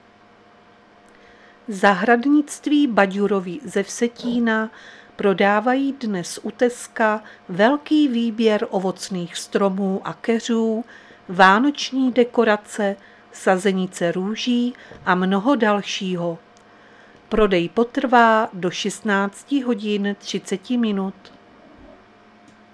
Záznam hlášení místního rozhlasu 15.11.2024
Zařazení: Rozhlas